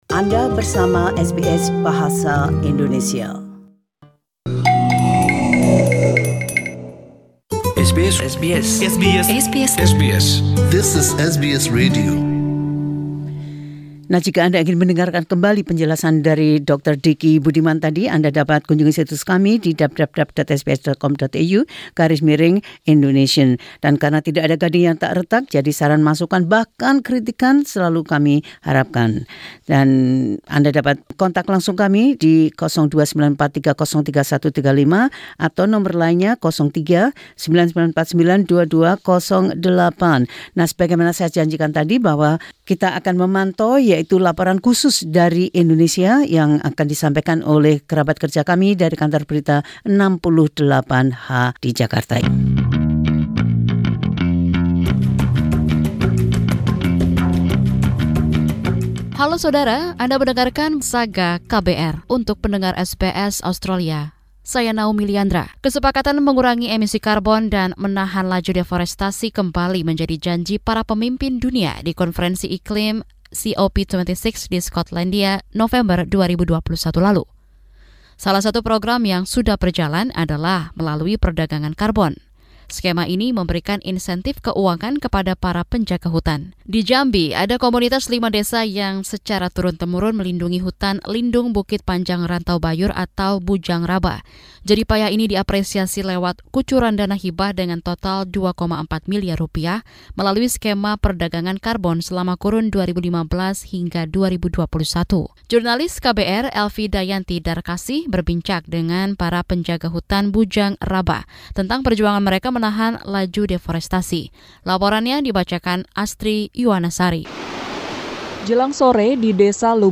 Tim KBR 68H melaporkan tentang bagaimana hal ini bermanfaat bagi masyarakat di daerah tersebut dalam beberapa hal terkait dengan ekonomi dan lingkungan.